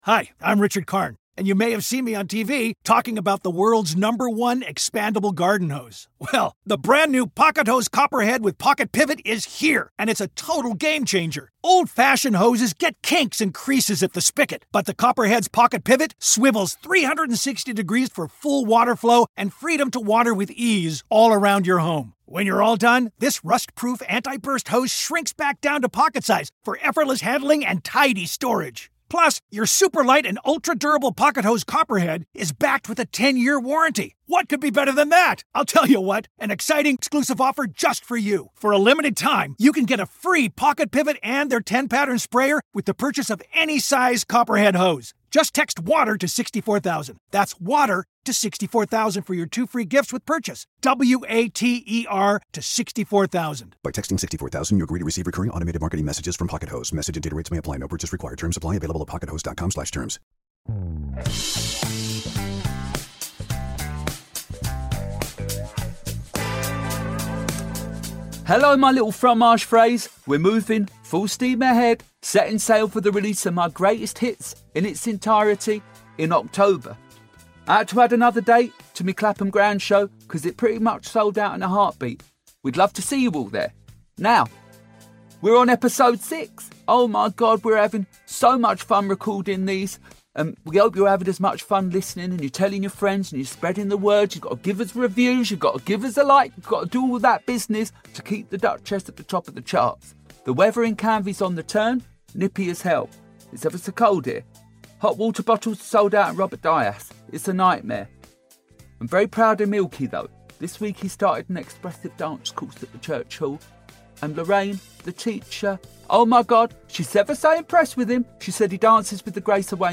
Diane Chorley is joined by Actor, Writer, Director and all round lovely man Mark Gatiss. Diane explores Mark's love for James Bond, his thrill of horror and how a working class boy from Durham made it in the industry.